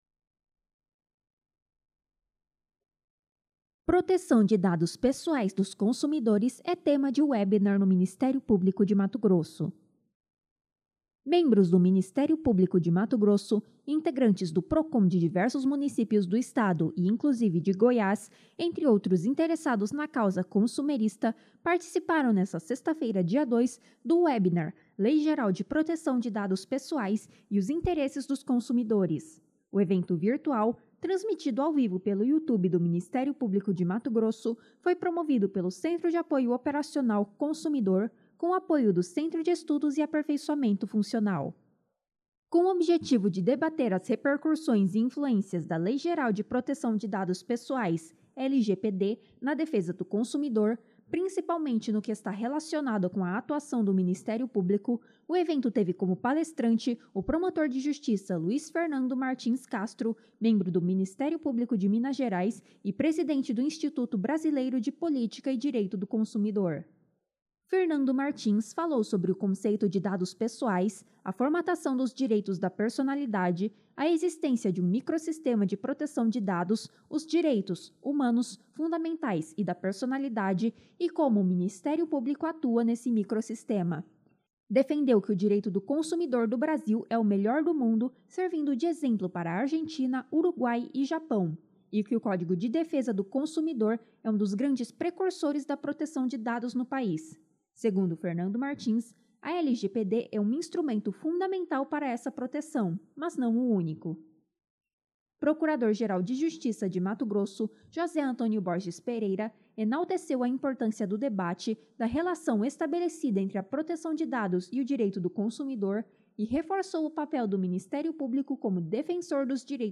Proteção de dados pessoais - webinar.mp3